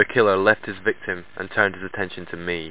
home *** CD-ROM | disk | FTP | other *** search / Horror Sensation / HORROR.iso / sounds / iff / leftvict.snd ( .mp3 ) < prev next > Amiga 8-bit Sampled Voice | 1992-09-02 | 25KB | 1 channel | 9,016 sample rate | 2 seconds